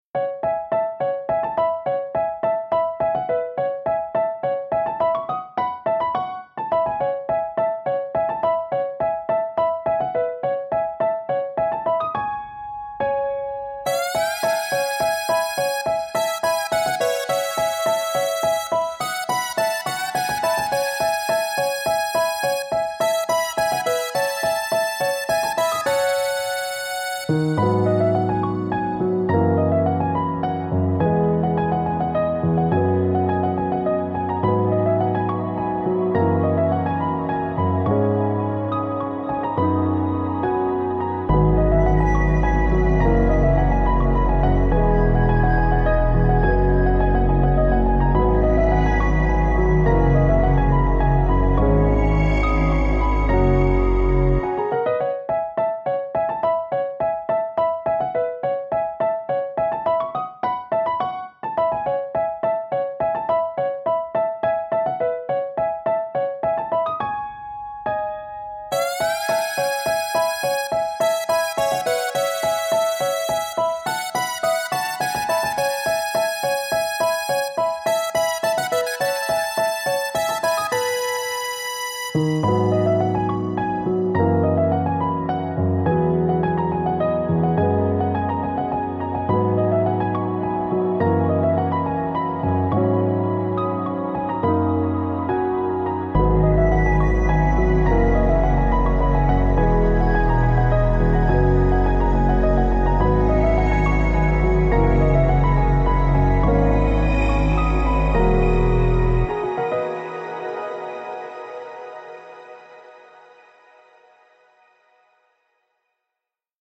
Ambient / Classical